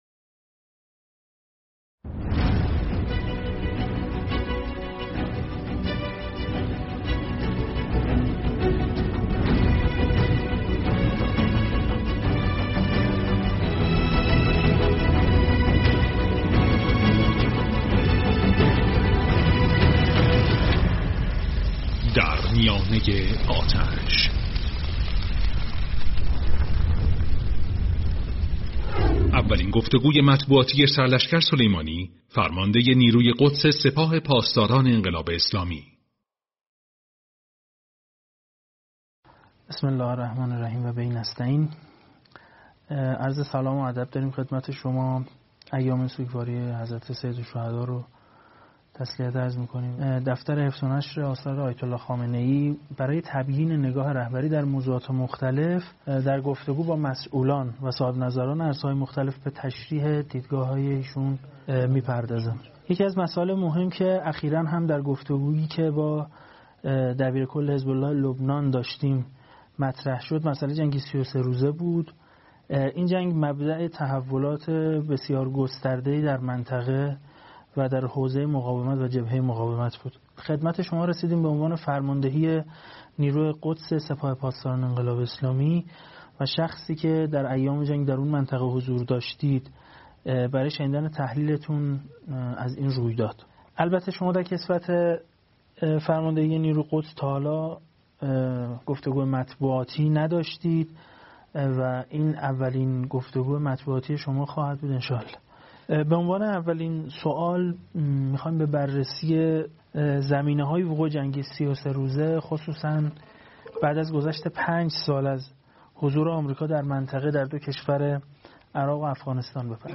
مستند در میانه آتش / گفتگو با شهید حاج قاسم سلیمانی |ضیاءالصالحین
مستند در میانه آتش اولین گفتگوی مطبوعاتی سردار شهید حاج قاسم سلیمانی به همراه اشارات و توصیه های رهبر انقلاب در جریان جنگ 33 روزه با رژیم صهیونیستی است...